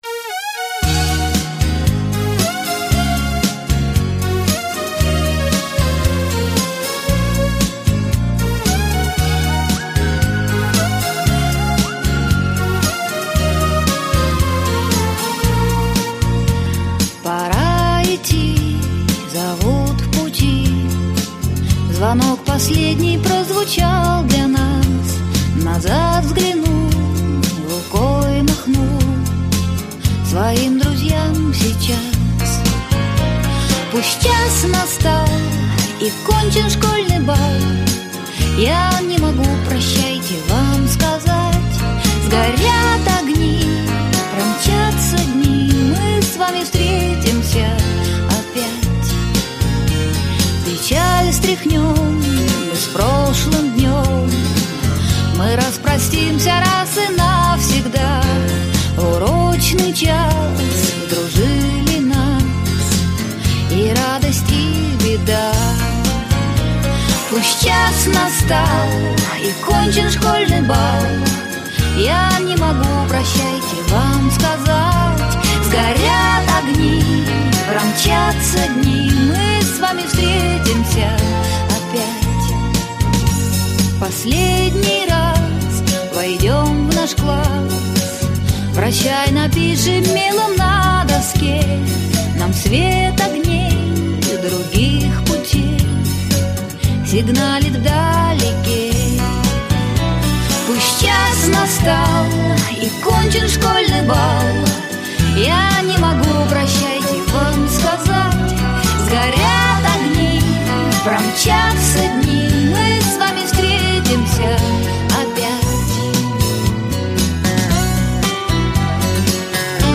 песня про школу.